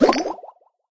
barkeep_throw_01.ogg